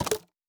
UI Tight 16.wav